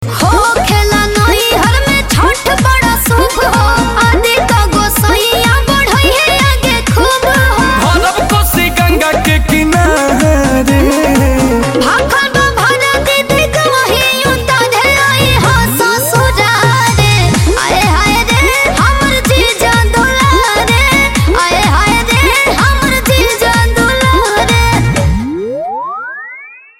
Bhojpuri Chhath Puja Ringtones